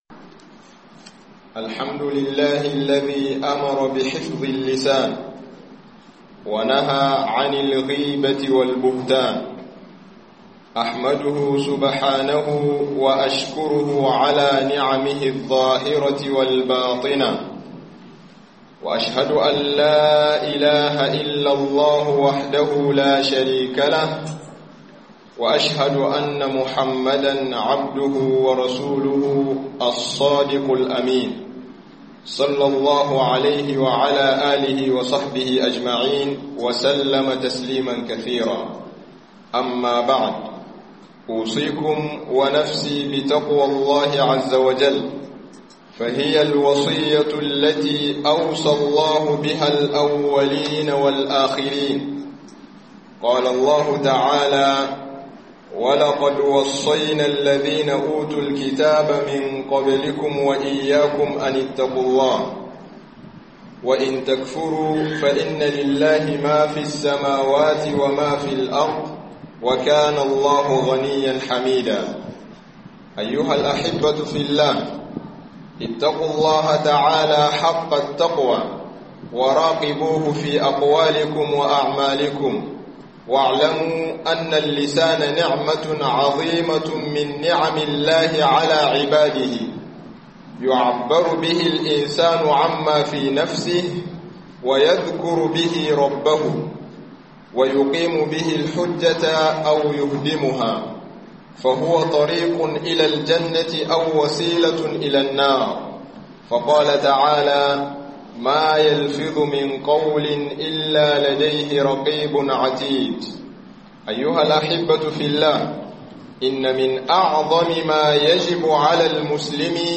Hudubobi